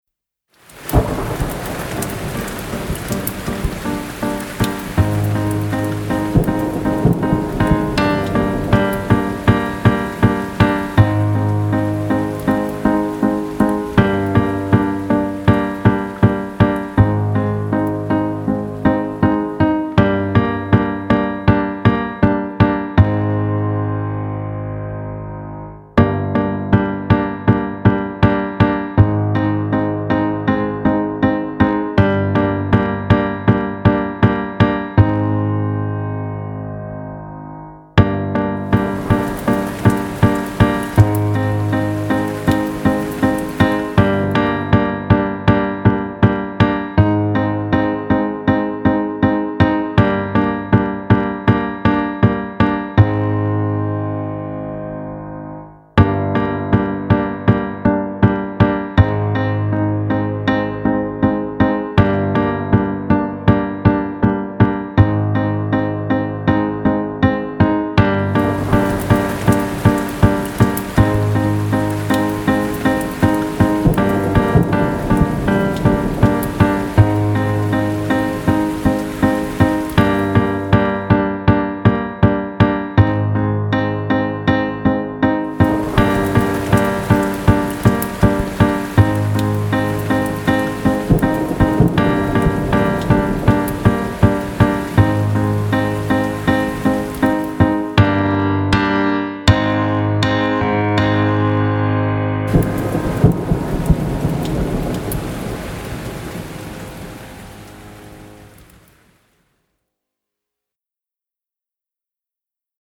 Instrumental version of song 3
intended to be used as a backing track for your performance.